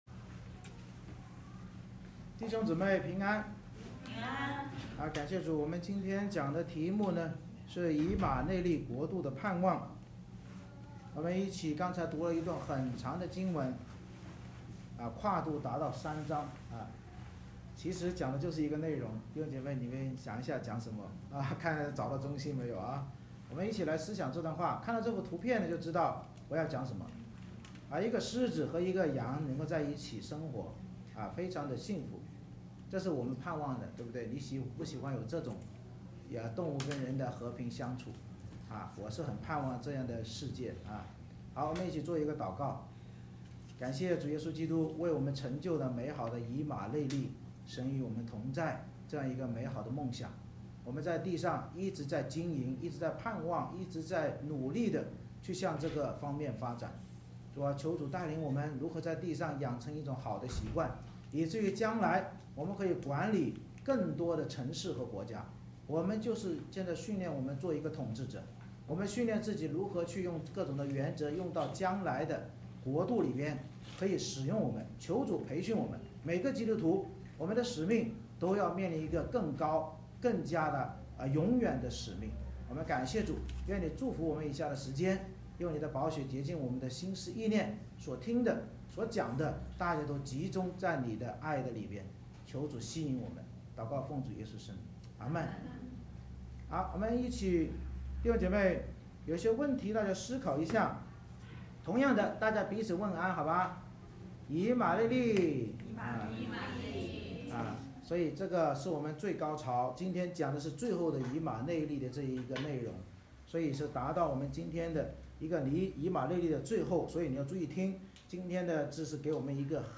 以赛亚书10:20-12:6 Service Type: 主日崇拜 先知关于耶西之根实现以马内利统治的预言